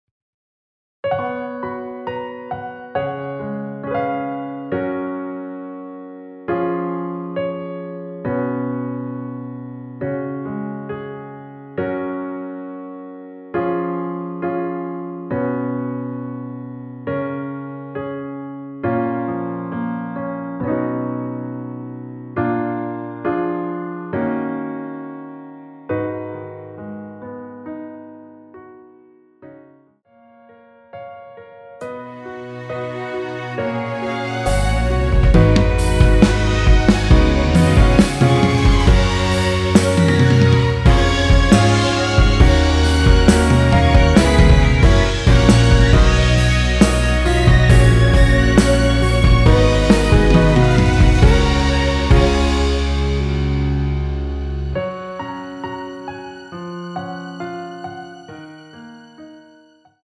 원키에서(+3)올린 MR입니다.
F#
앞부분30초, 뒷부분30초씩 편집해서 올려 드리고 있습니다.
중간에 음이 끈어지고 다시 나오는 이유는